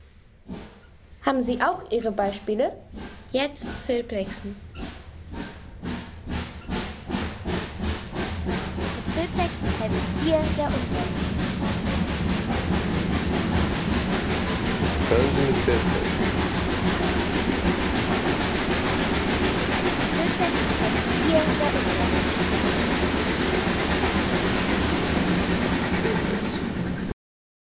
Zugkraft der Dampflokomotive